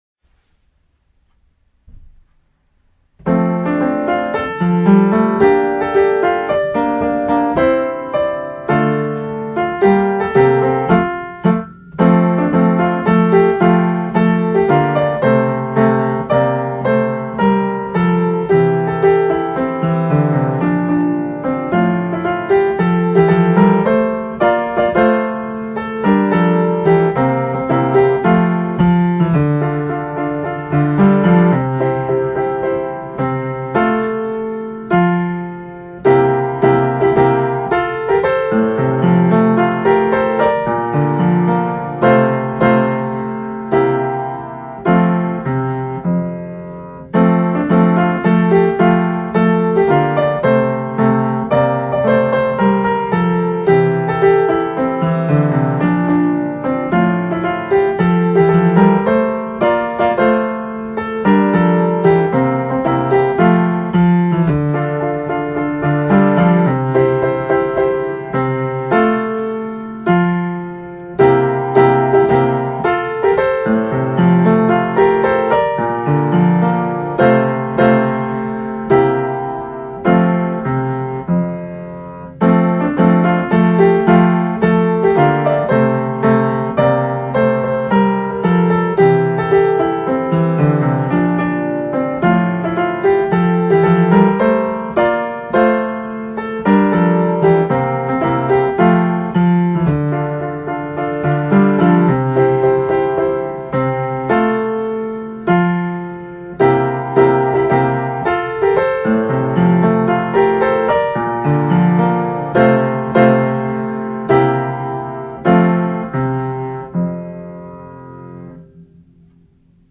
校歌
村上政三　作詞
石井義信　作曲